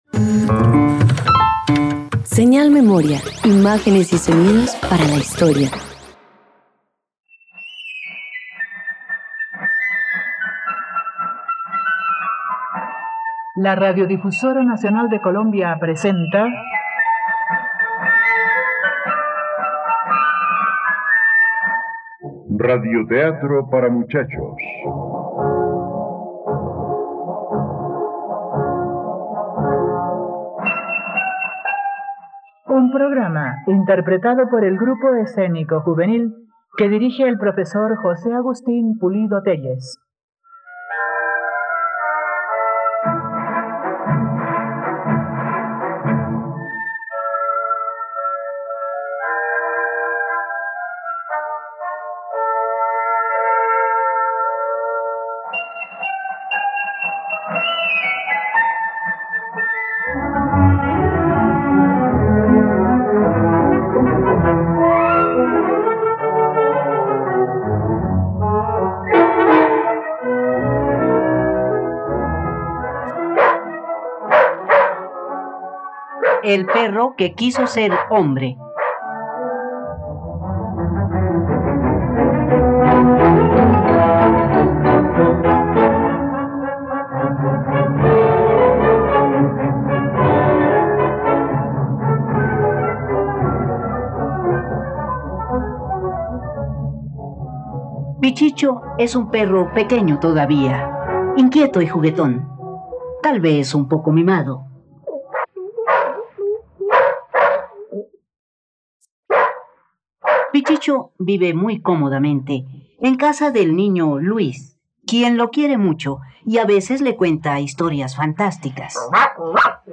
El perro que quería ser hombre - Radioteatro dominical | RTVCPlay
radioteatro